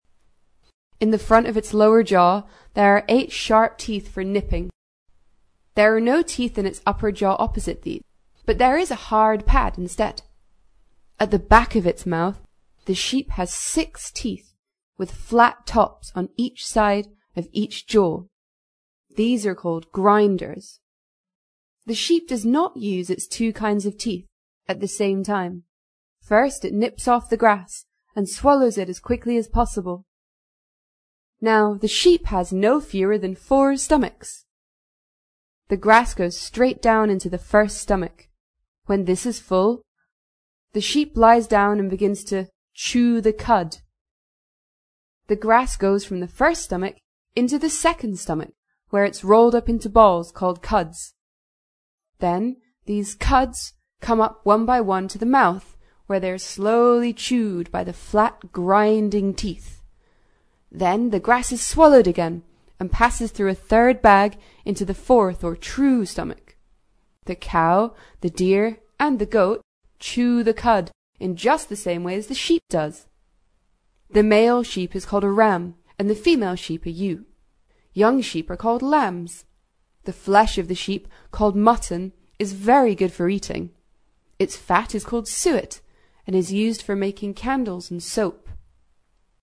在线英语听力室英国学生科学读本 第36期:浑身是宝的绵羊(2)的听力文件下载,《英国学生科学读本》讲述大自然中的动物、植物等广博的科学知识，犹如一部万物简史。在线英语听力室提供配套英文朗读与双语字幕，帮助读者全面提升英语阅读水平。